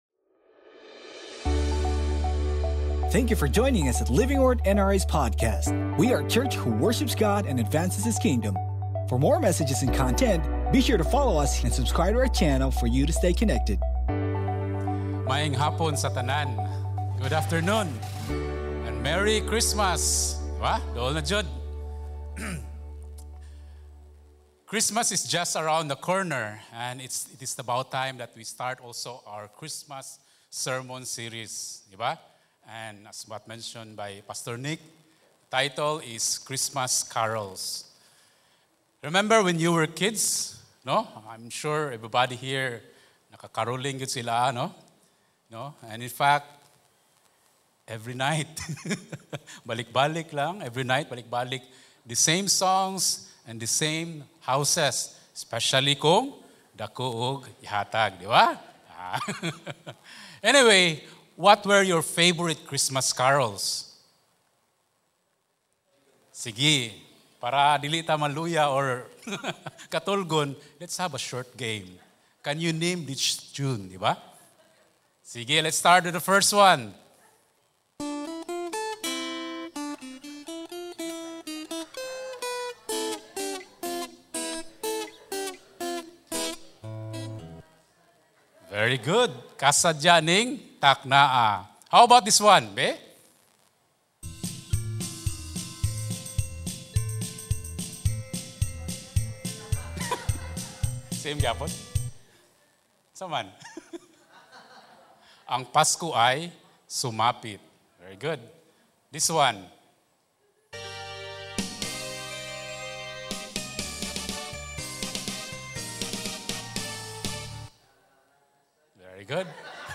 Sermon Title: O HOLY NIGHT Scripture Text: LAMENTATIONS 3:21-26 Sermon Series: CHRISTMAS CAROLS Sermon Notes: You can know the story of Christmas without knowing the Christ.